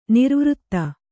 ♪ nirvřtta